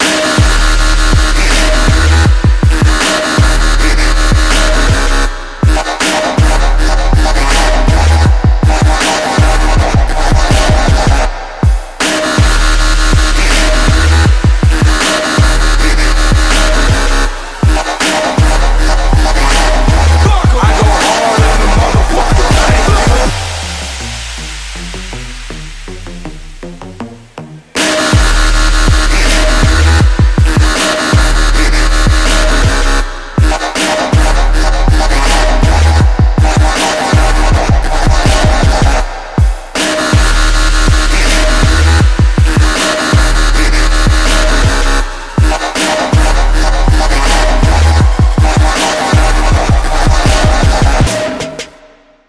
• Качество: 320, Stereo
жесткие
без слов